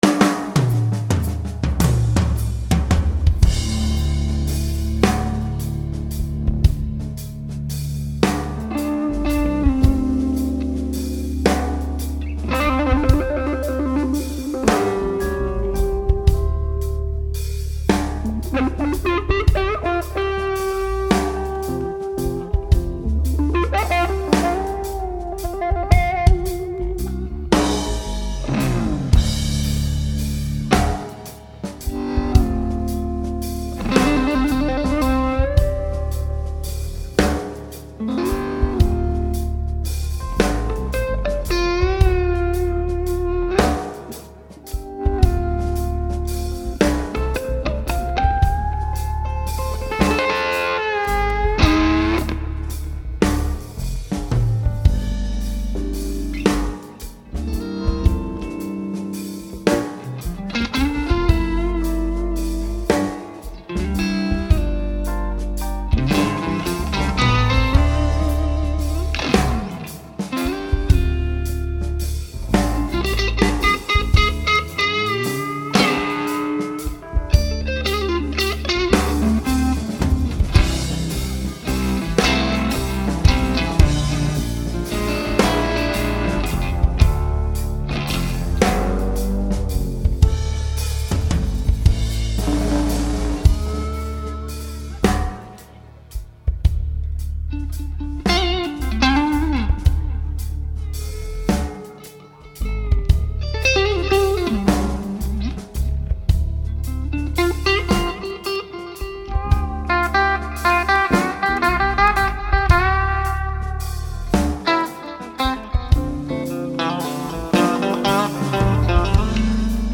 This was with his own group.
Done with Mackie Hard Disk Recorders.